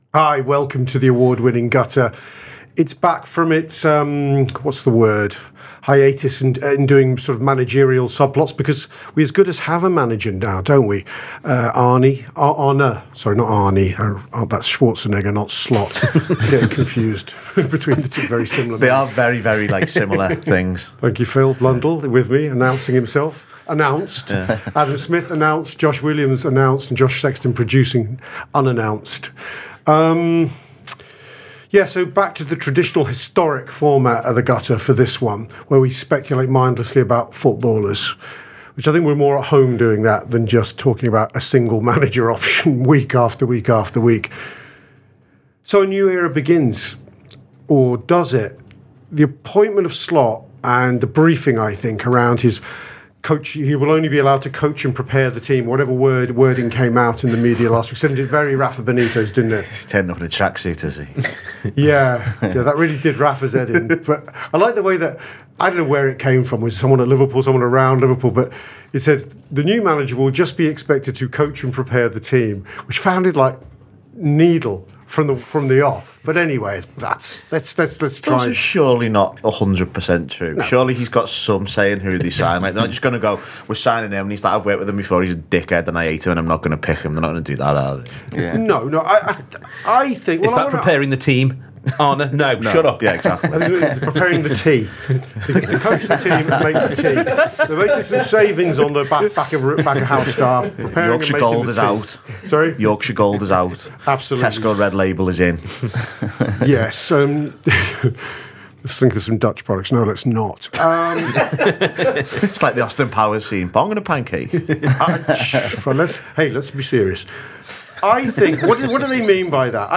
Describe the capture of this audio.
Below is a clip from the show – subscribe for more on Liverpool’s summer transfer window…